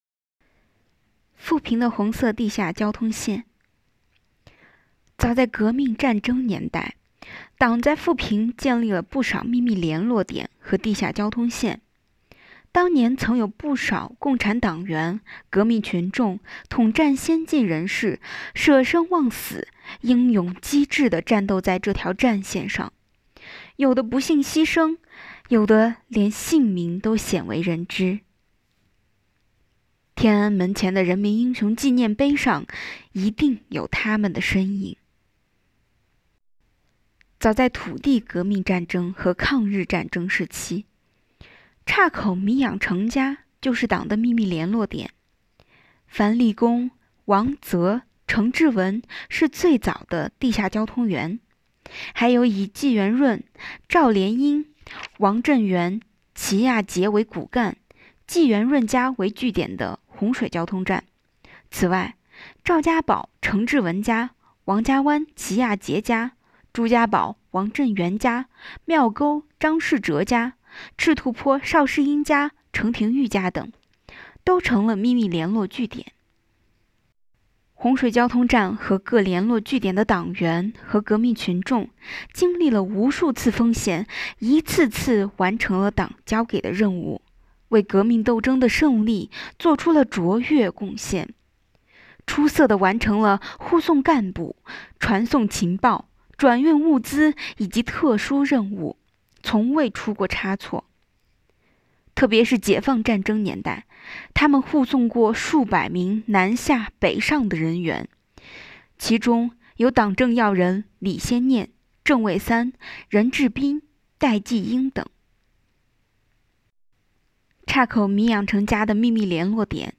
【红色档案诵读展播】富平的红色地下交通线